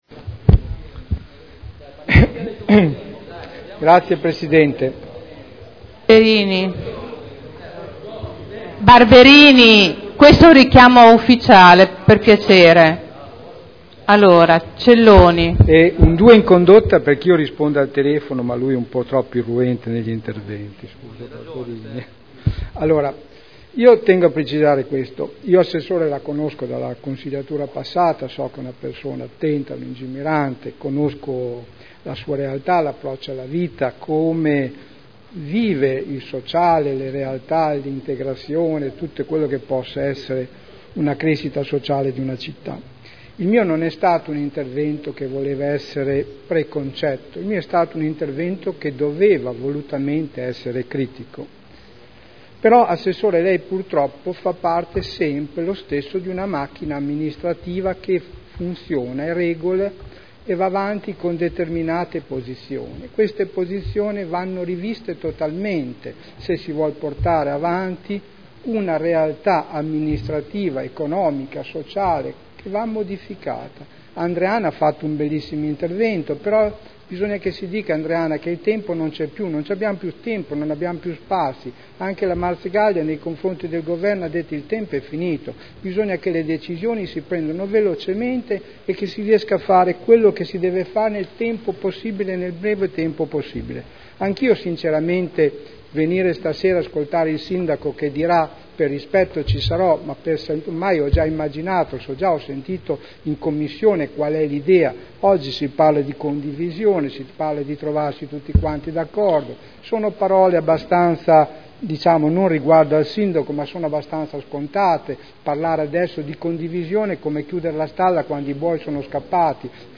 Seduta del 26 settembre 2011 Bilancio di Previsione 2011 - Bilancio Pluriennale 2011-2013 - Programma triennale dei Lavori Pubblici 2011-2013 - Stato di attuazione dei programmi e verifica degli equilibri di bilancio - Variazione di Bilancio n. 2 Dichiarazione di voto